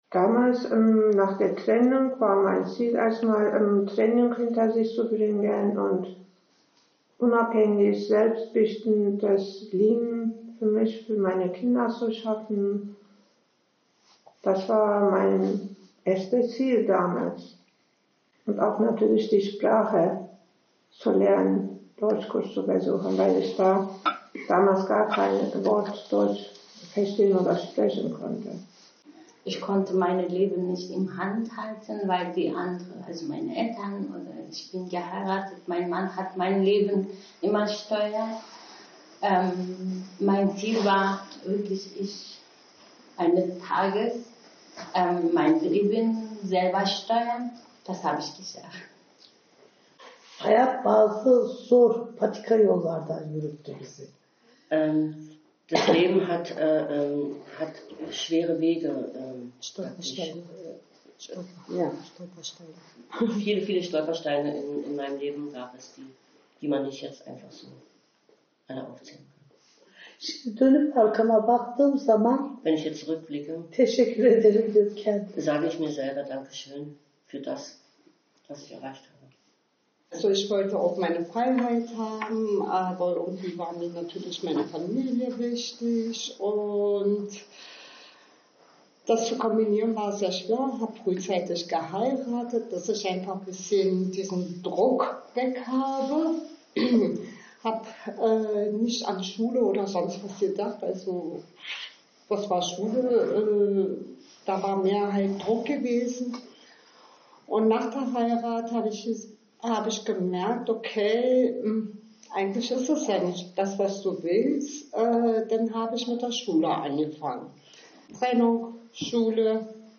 Von Partnergewalt sich befreiende Frauen erzählen vom Mut, ins Ungewisse zu gehen – und von den ersten Momenten des Ankommens im Frauenhaus.